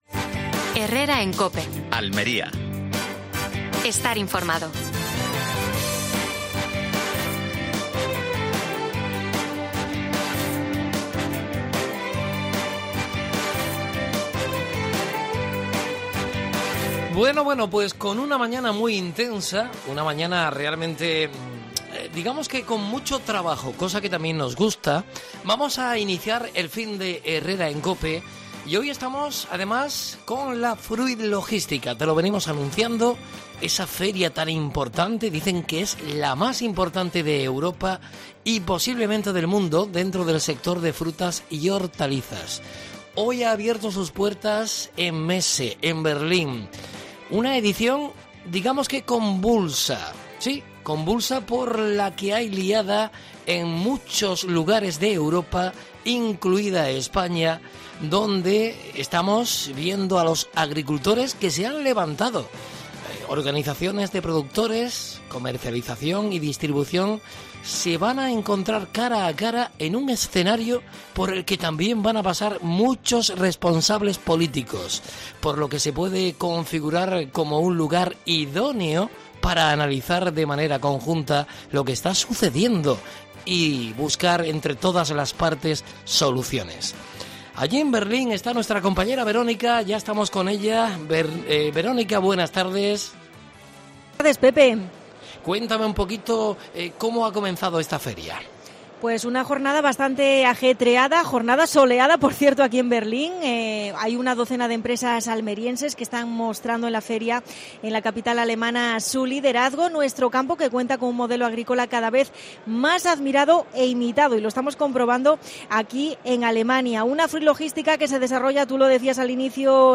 Última hora en Almería. Fruit Logística desde Berlín.